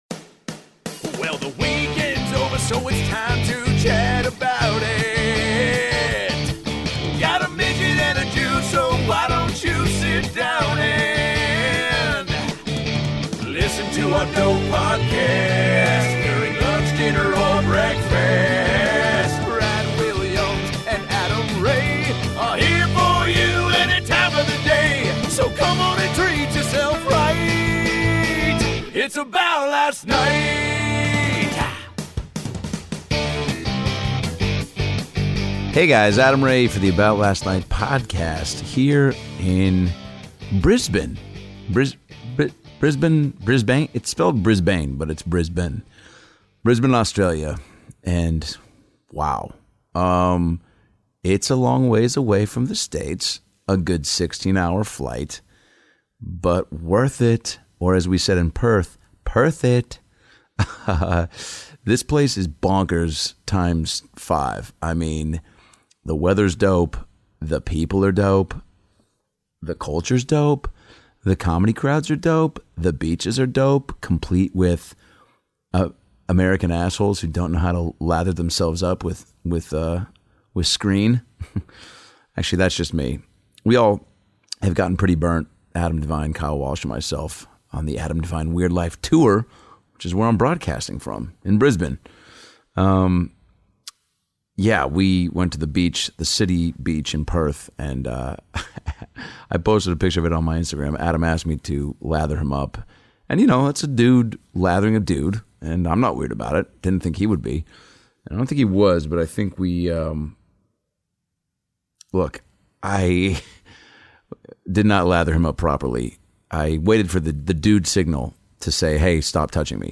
This week Adam records a solo episode from down under! Adam gives his first impressions of Australia, his experience at a Koala sanctuary, partying with the locals on Australia Day, hotel drama, and some other good stuff!